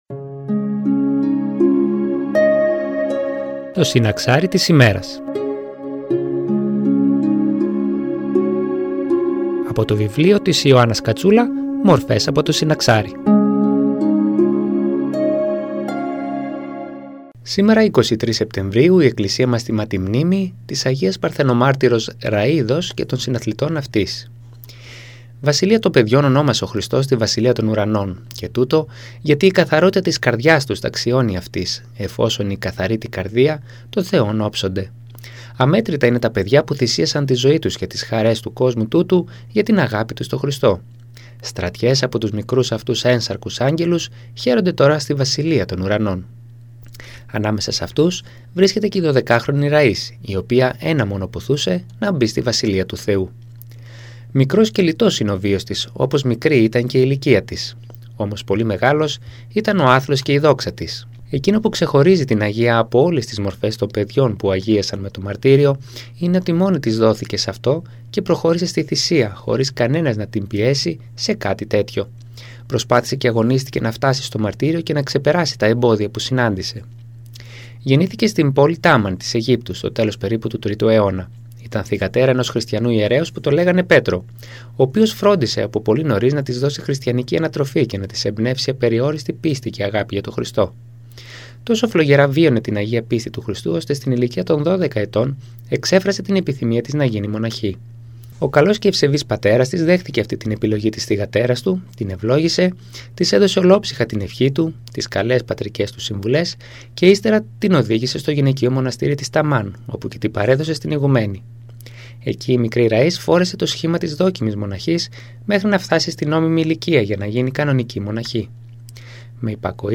Η παρούσα ομιλία έχει θεματολογία «23 Σεπτεμβρίου – Η Αγία Ραΐς»
Εκκλησιαστική εκπομπή